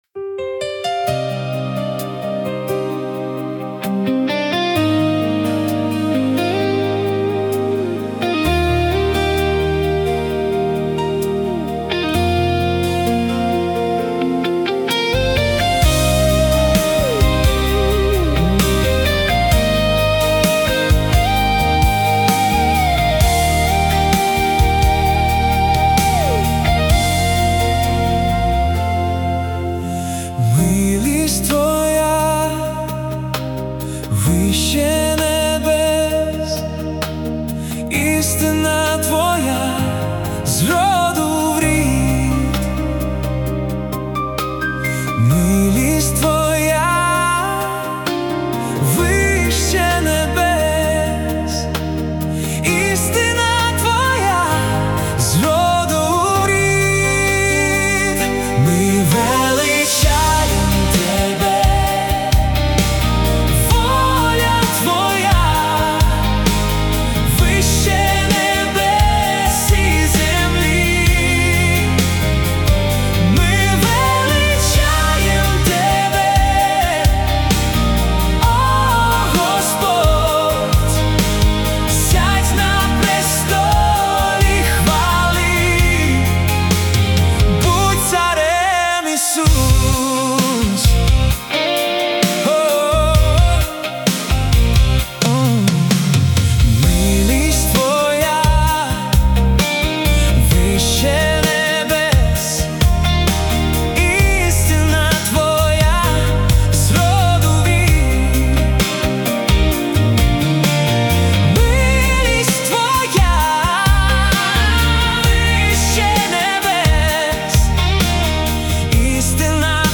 песня ai